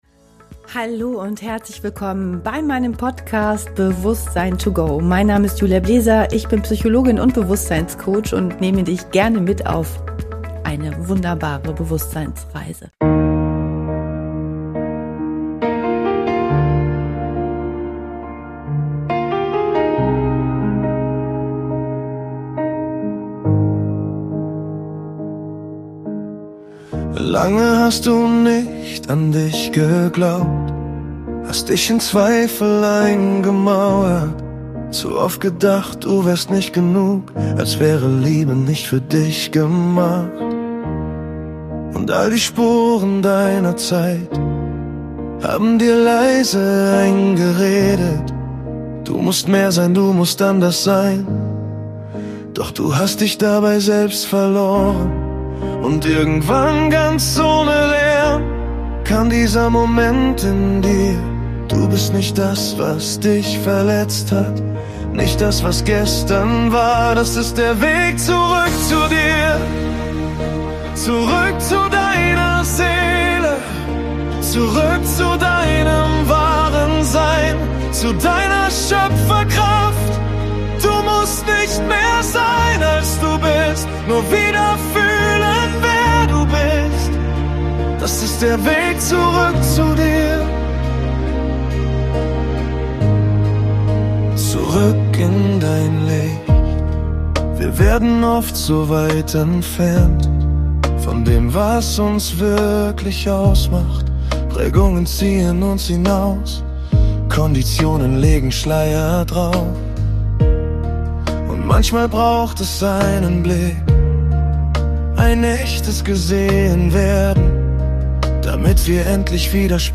Ein stärkender MINDCLEANSE Motivationssong – als Bonusmaterial zur emotionalen Ausrichtung und inneren Kraft.